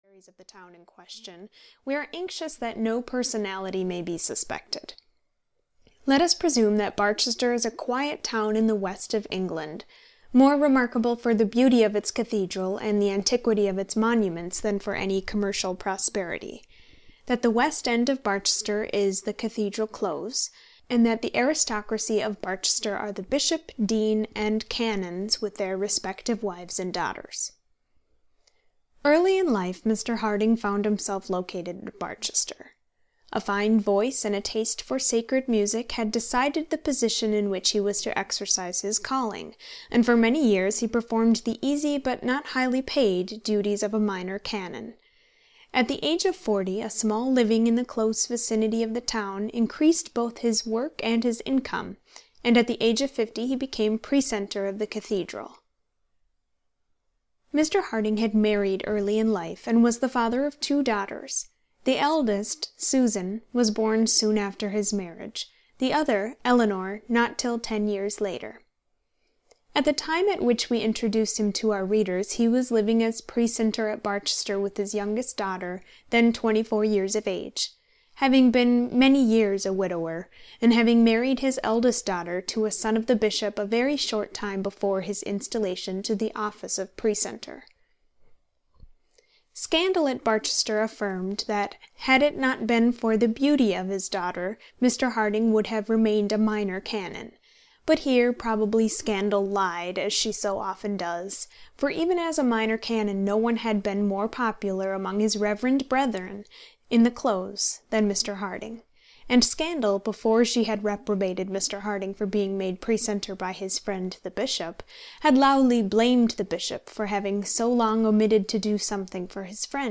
The Warden (EN) audiokniha
Ukázka z knihy